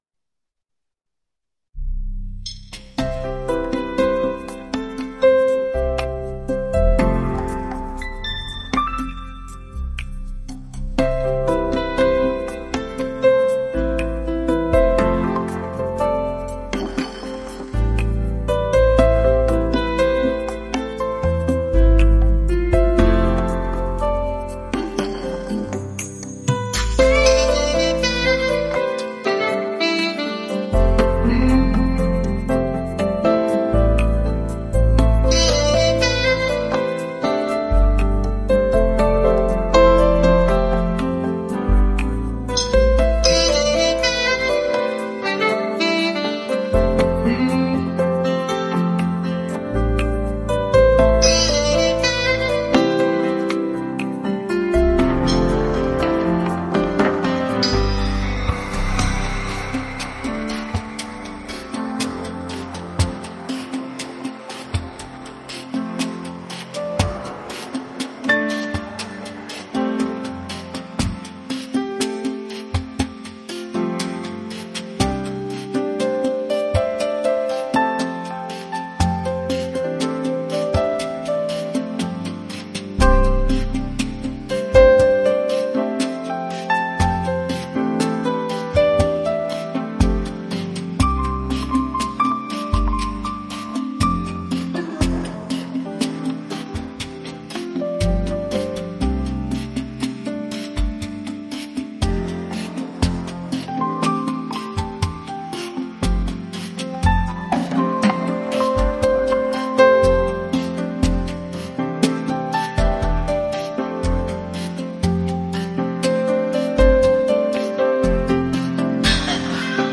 ・メインセクション: ピアノとサックスの柔らかなメロディが中心となり、スムースなビートが曲の進行を支えます。
・アウトロ: 再び穏やかなテンポに戻り、リラックスした雰囲気を保ちながらフェードアウトします。